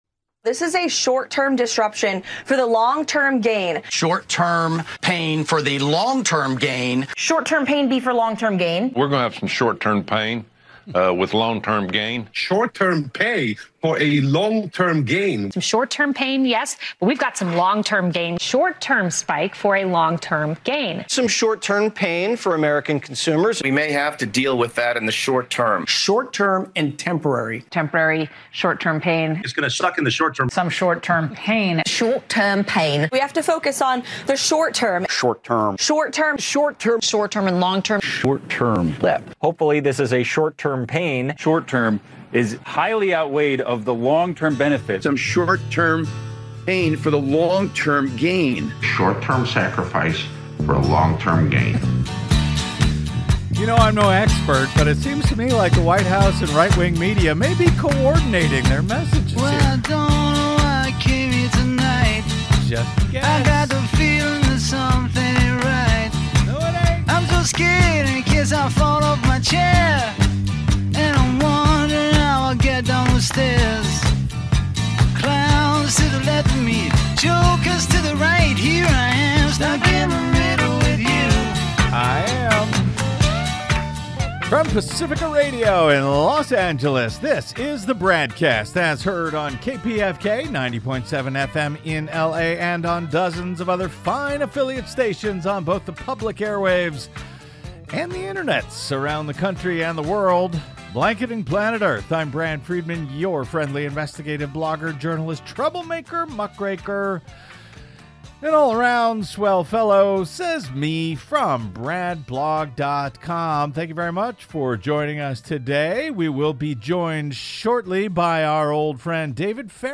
Guest: Political scientist